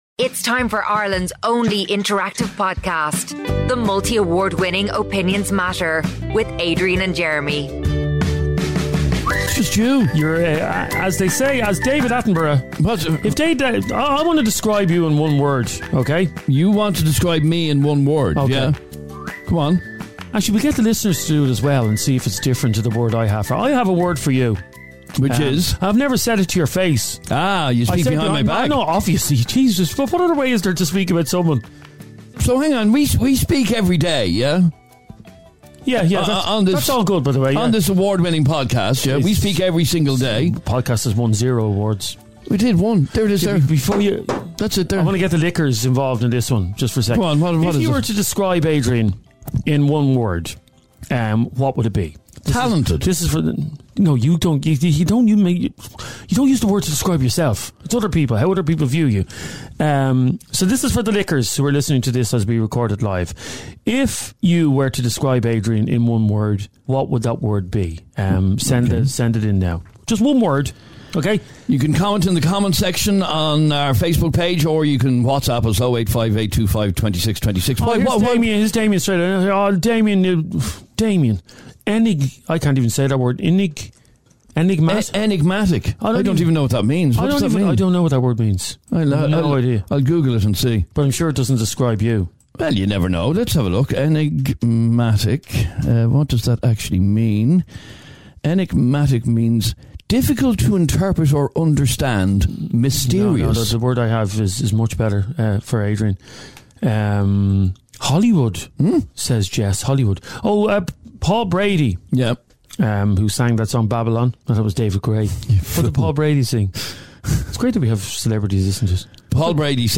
and its completely UNCUT and UNCENSORED ..